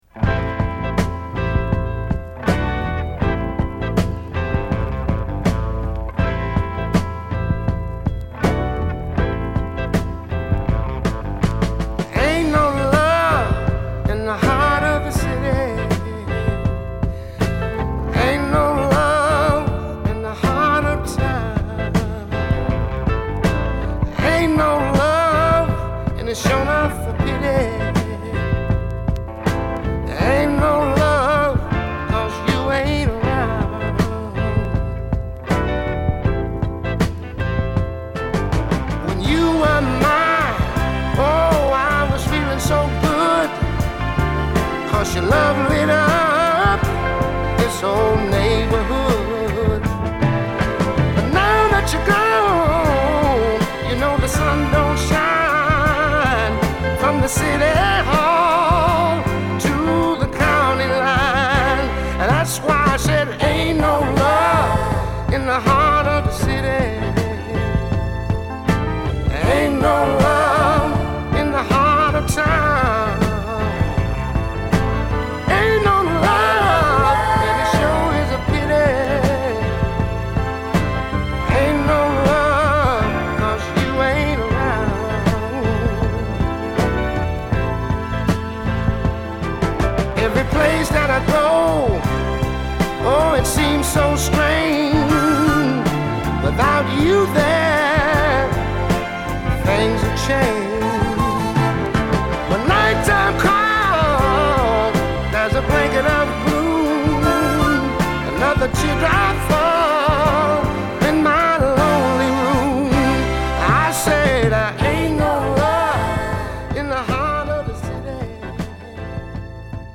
ゆったりしつつも骨太な演奏と、ソウルフルなヴォーカルが絡んだ名曲です。